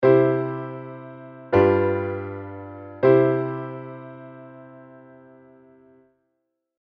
ドミナントモーションコード進行の一番基本的な動きですね。
起立・礼・着席 でも使われていますねっ。